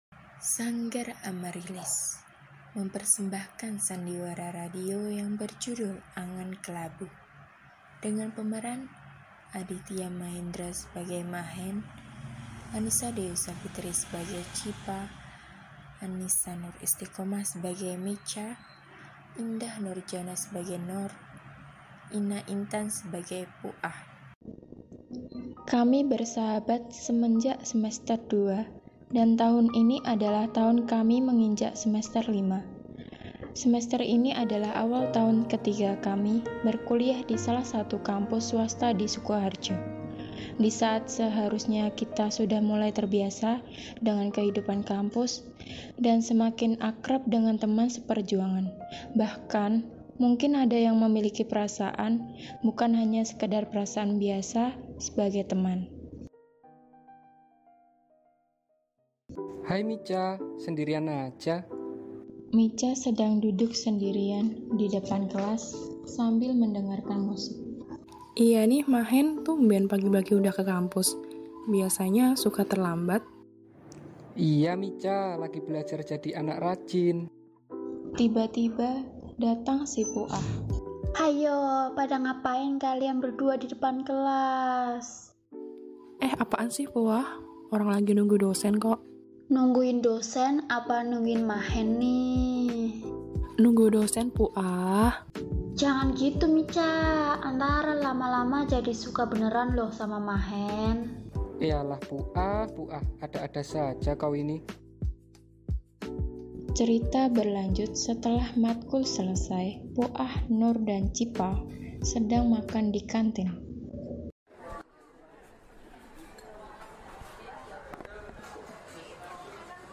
Sebuah Drama Radio yang menceritakan tentang perasaan saling suka diantara 5 sahabat.